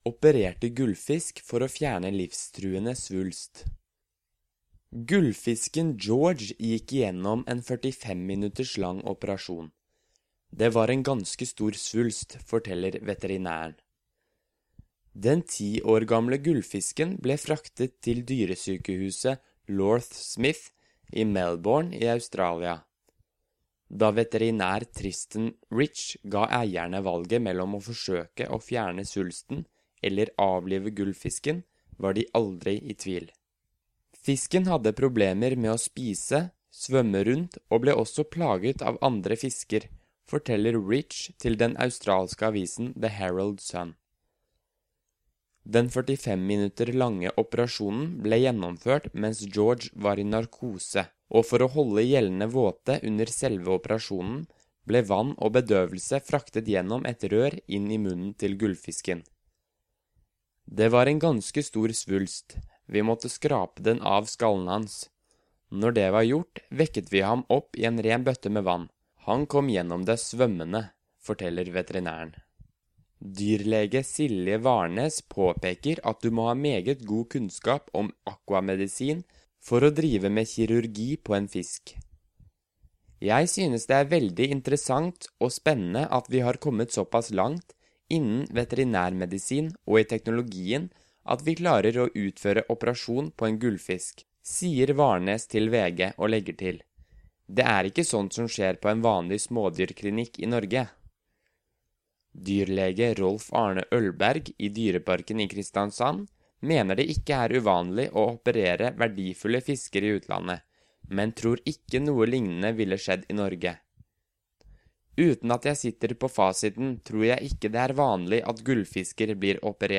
Learn Norwegian by listening to a native Norwegian while you’re reading along.